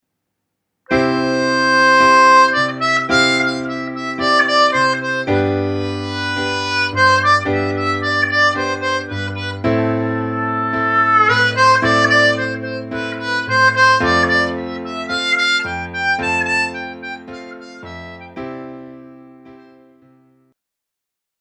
• использовать повторяющиеся ноты (причём можно это делать, используя вышеизложенные два пункта: ноты разной длительности и с использованием пауз)